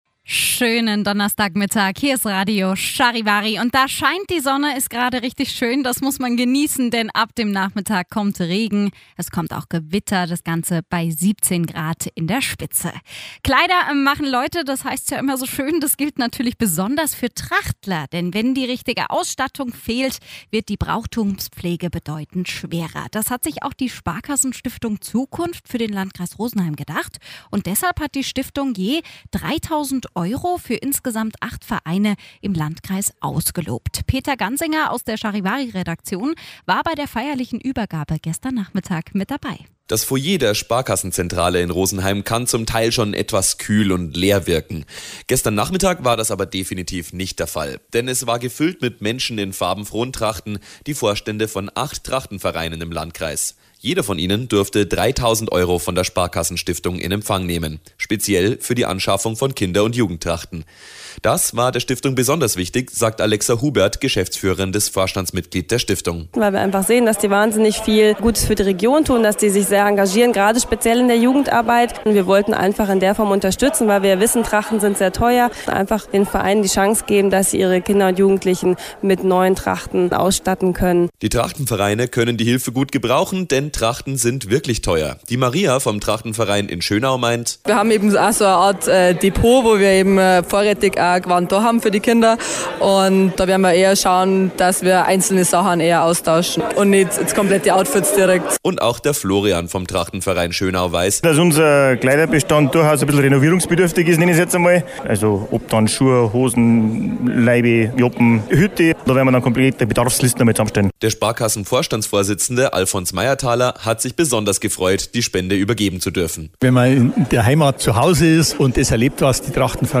Bericht von Radio Charivari: